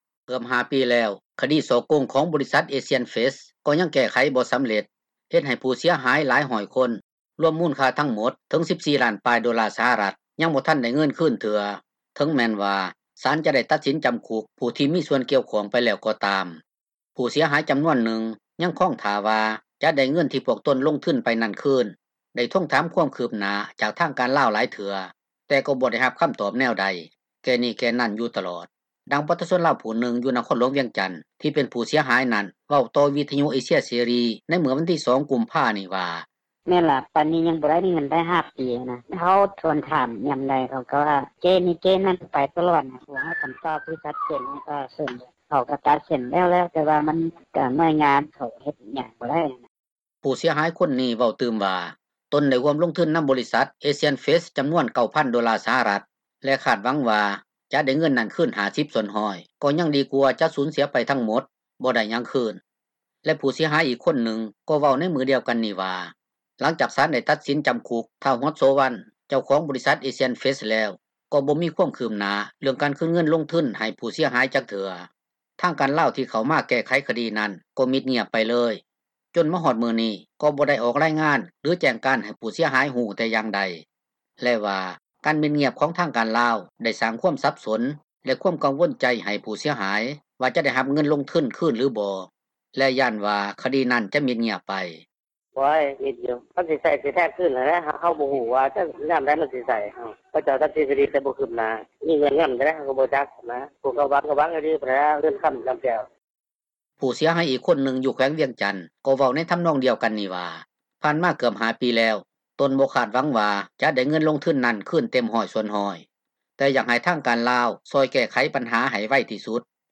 ຜູ້ເສັຍຫາຍຈໍານວນນຶ່ງ ຍັງຄອງຖ້າວ່າຈະໄດ້ເງິນທີ່ພວກຕົນລົງທຶນໄປນັ້ນຄືນ, ໄດ້ທວງຖາມຄວາມຄືບໜ້າ ຈາກທາງການລາວຫລາຍເທື່ອ ແຕ່ກໍບໍ່ໄດ້ຮັບຄໍາຕອບແນວໃດ ແກ້ນີ້ແກ້ນັ້ນຢູ່ຕລອດ ດັ່ງປະຊາຊົນລາວຜູ້ນຶ່ງ ຢູ່ນະຄອນຫລວງວຽງຈັນ ທີ່ເປັນຜູ້ເສັຍຫາຍນັ້ນ ເວົ້າຕໍ່ວິທຍຸເອເຊັຽເສຣີ ໃນມື້ວັນທີ 2 ກຸມພານີ້ວ່າ: